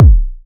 • Round Kickdrum D# Key 43.wav
Royality free kick single shot tuned to the D# note. Loudest frequency: 144Hz
round-kickdrum-d-sharp-key-43-Sk4.wav